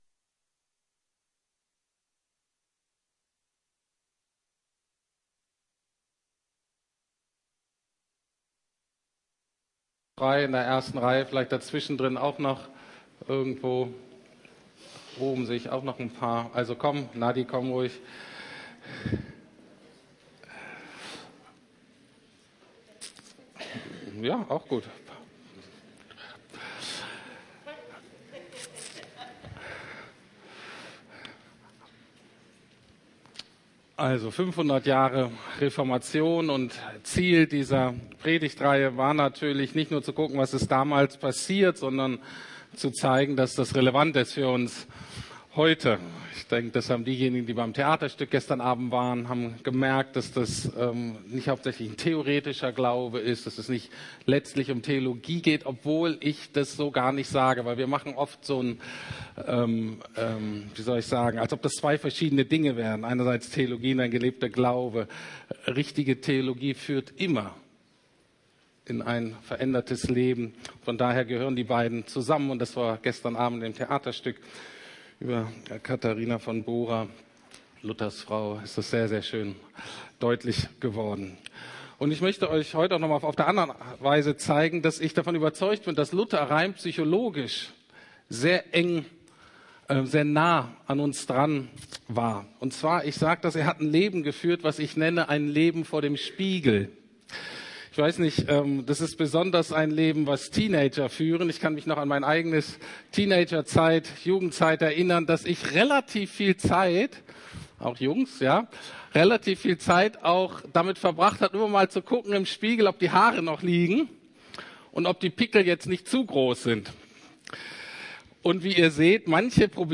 Gott allein die Ehre - Soli Deo Gloria ~ Predigten der LUKAS GEMEINDE Podcast